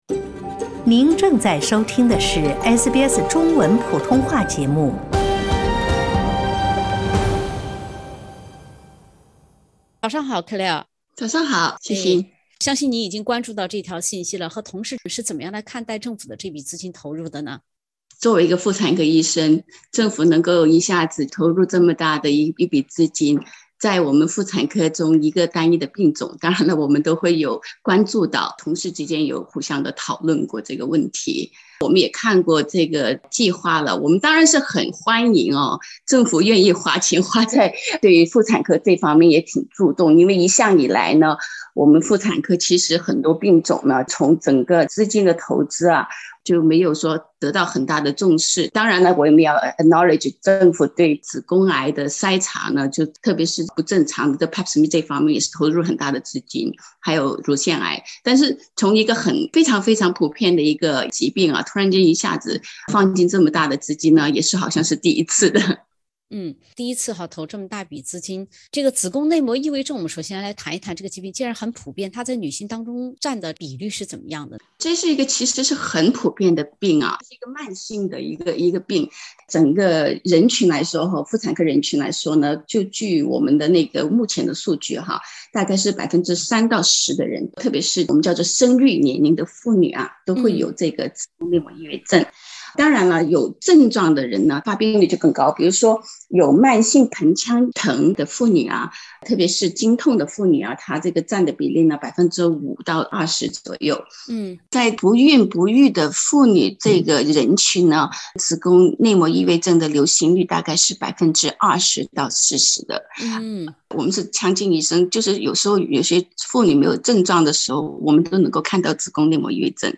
（點擊封面圖片，收聽完整對話）